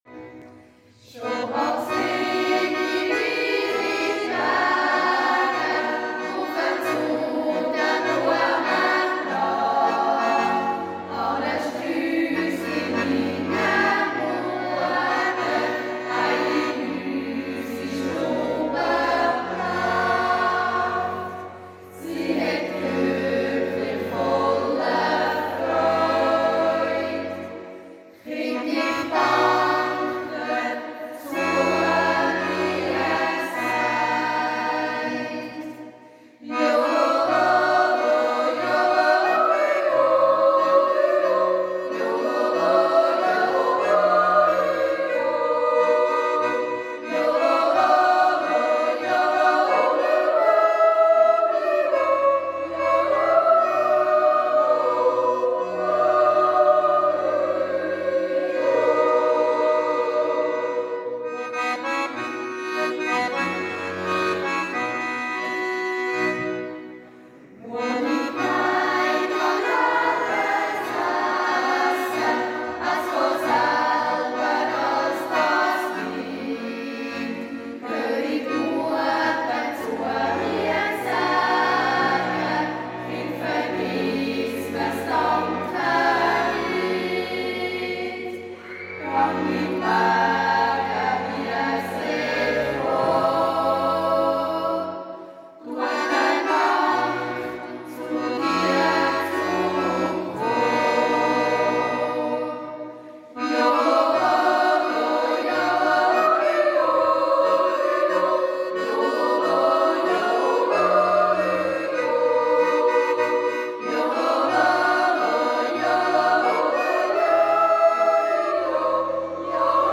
Eidg. Dank-, Buss- und Bettag mit Erntedank am 21.09.2025
haben sie unsere Kirche mit ihren jugendlichen Stimmen eindrücklich erfüllt.
Liveausschnitt
jungjutzer.mp3